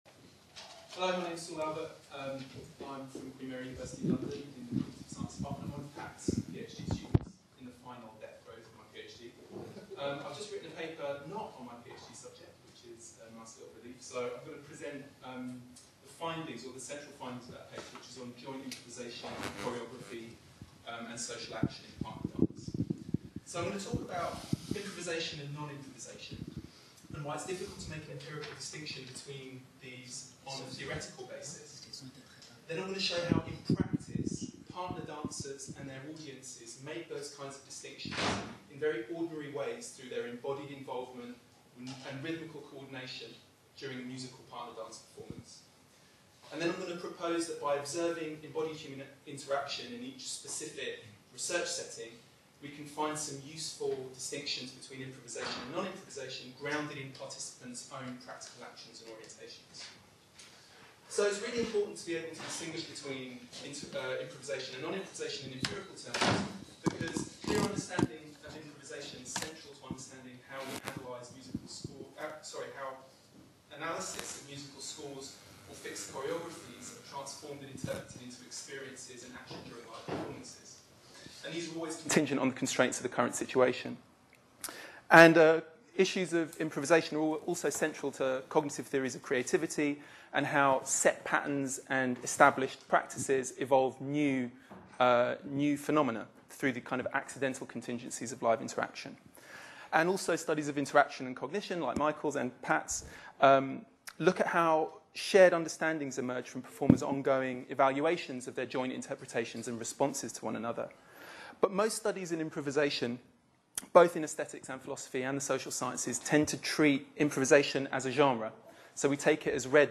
“Quantifying JI”Short talk 1.1